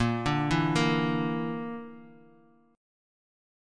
BbMb5 chord